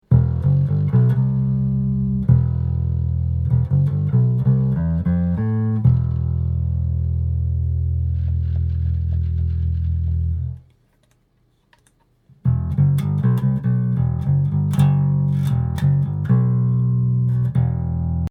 Der zerrt bei kräftig angeschlagenen tiefen Tönen.
Zu erst der Neck PU mit dem Geräusch, ist mehr ein Brutzeln als Zerren. Ich habe dann mal den PU bewegt während der Ton noch klingt, dann gibt es auch Geräusche.
Und dann noch der Steg PU, bei dem ist alles still.